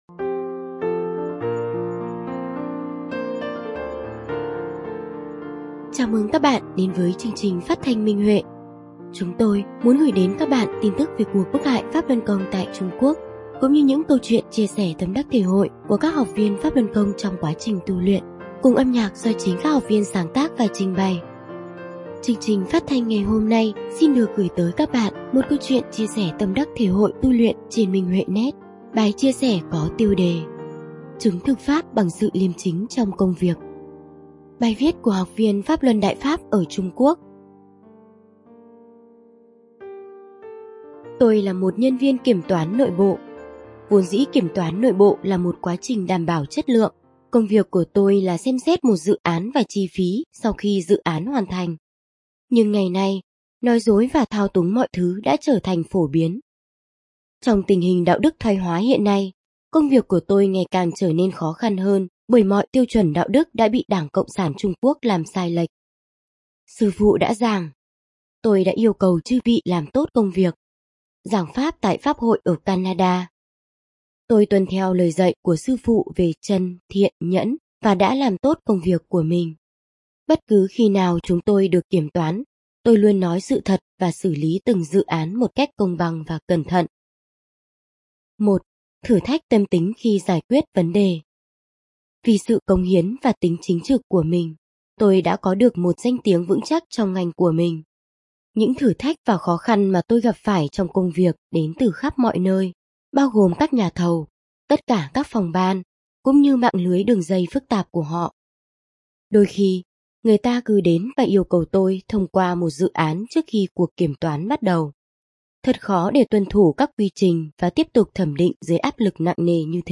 Phát thanh Minh Huệ (Câu chuyện tu luyện): Chứng thực Pháp bằng sự liêm chính trong công việc